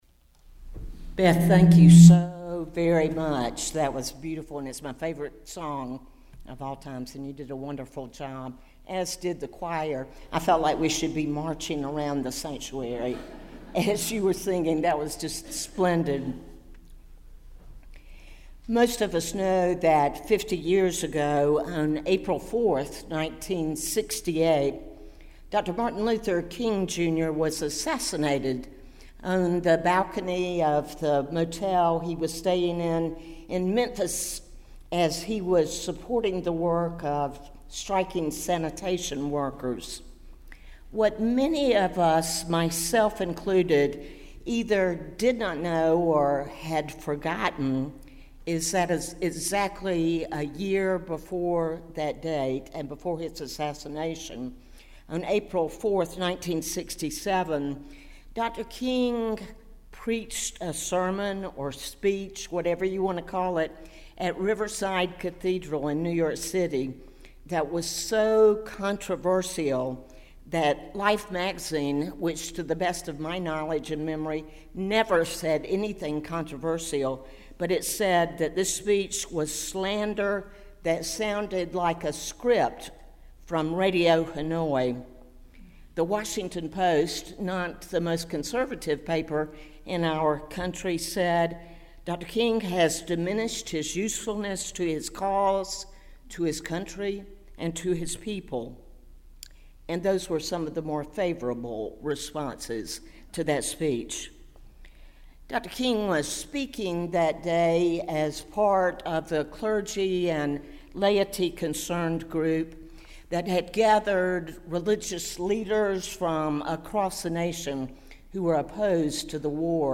As we celebrate Memorial Day Weekend, this service will provide us with an opportunity to examine Dr. King’s profound insights regarding the interconnectedness of racism, militarism, and extreme materialism. Our challenge is to offer a prophetic critique of this aspect of our national culture without calling into question the patriotism and sacrifice of those who have served or do serve in the military.